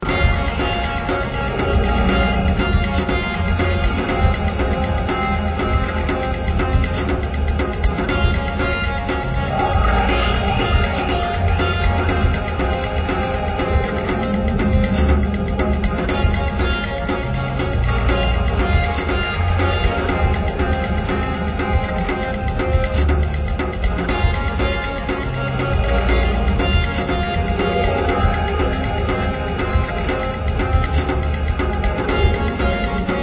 Royalty Free Music for use in any type of